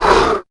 Heroes3_-_Crimson_Couatl_-_HurtSound.ogg